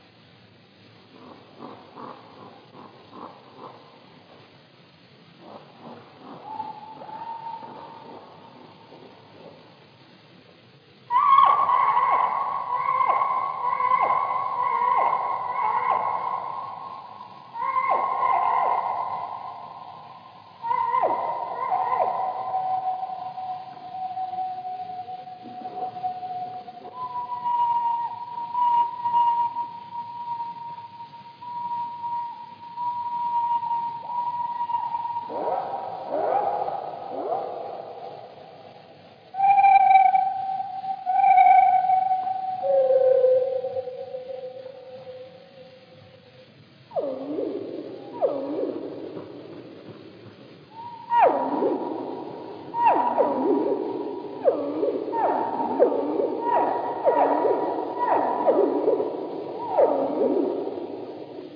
Proto až v osminásobném zpomalení objevíme rozmanitost ptačího hlasu.